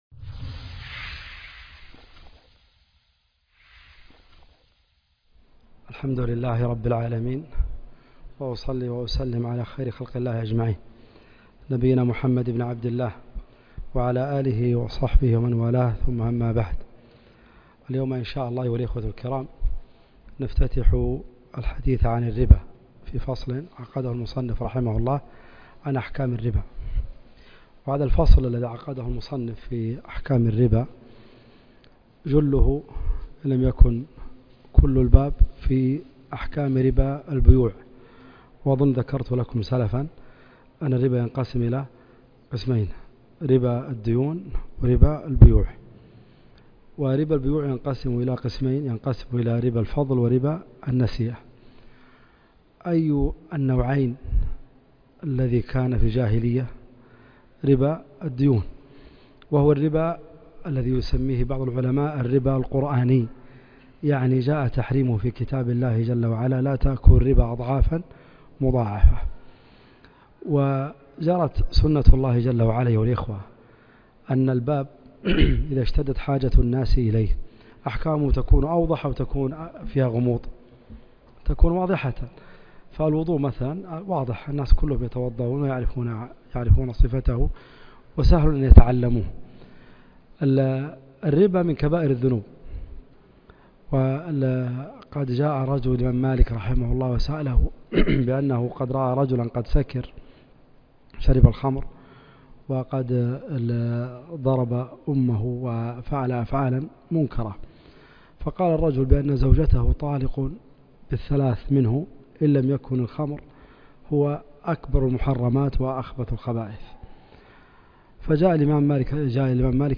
شرح كتاب أخصر المختصرات المجلس 45